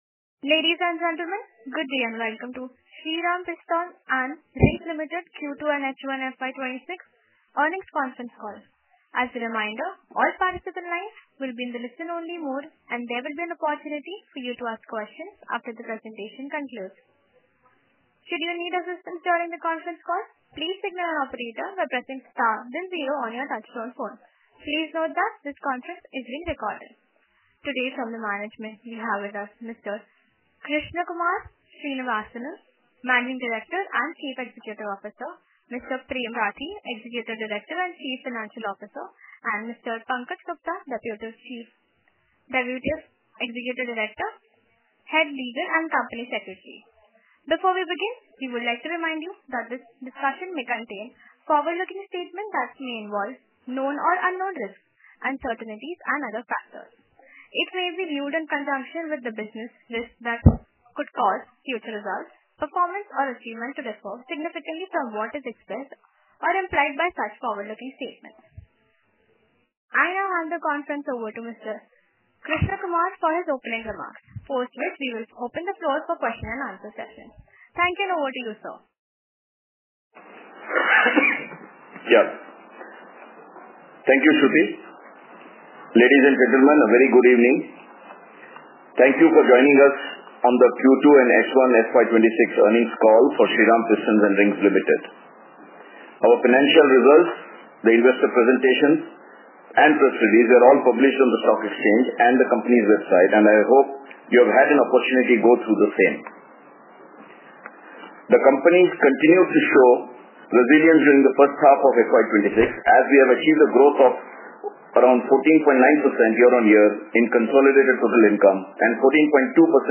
Earnings Call – Audio Recording Q3 9M FY26 held on February 3, 2026.
SPRL_Q2FY26_EarningsCallAudio-1.mp3